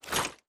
Grenade Sound FX
Throw1.wav